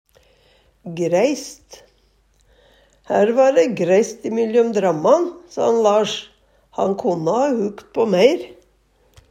greist - Numedalsmål (en-US)